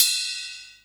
44 CYMB 1 -L.wav